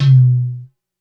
Index of /90_sSampleCDs/300 Drum Machines/Korg DSS-1/Drums01/06
MedTom.wav